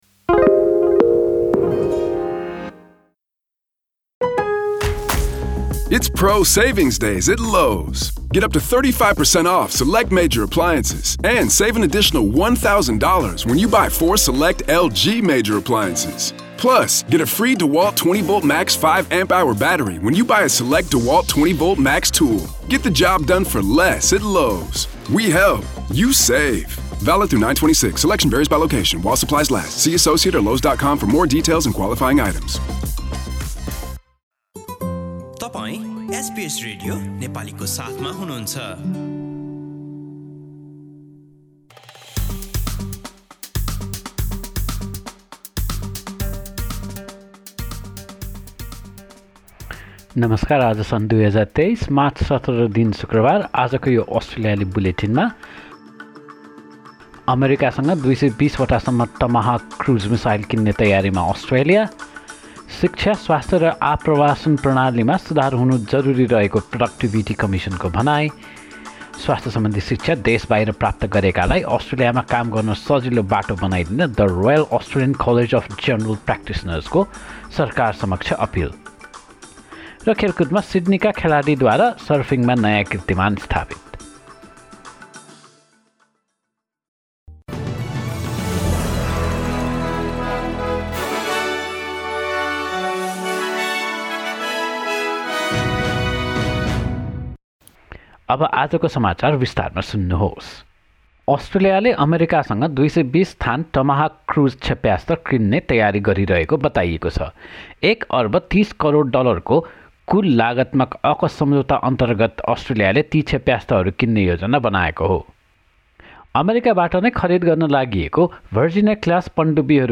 एसबीएस नेपाली अस्ट्रेलिया समाचार: शुक्रवार १७ मार्च २०२३